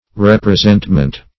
Representment \Rep`re*sent"ment\ (-ment)